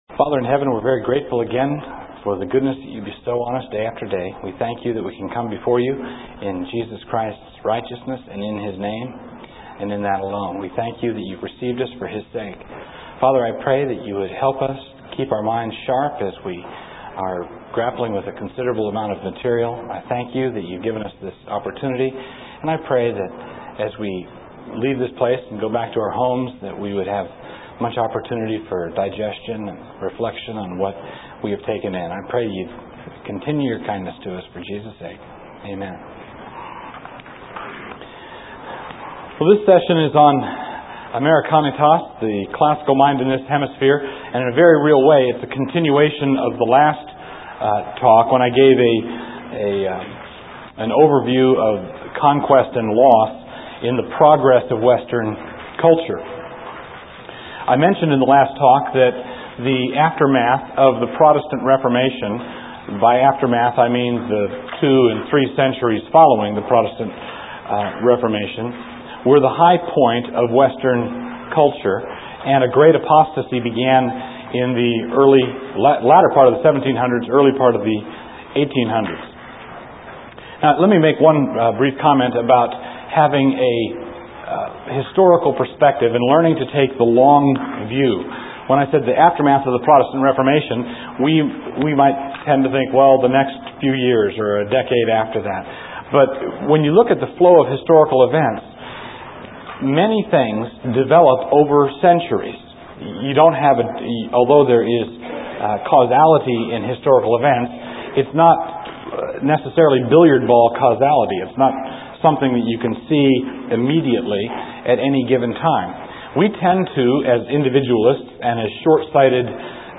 1996 Foundations Talk | 0:55:34 | All Grade Levels, Culture & Faith